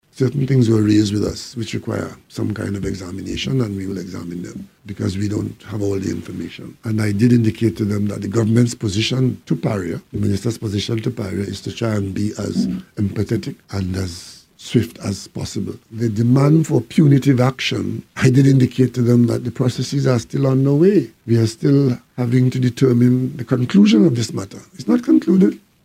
He elaborated further during a post-cabinet briefing this afternoon.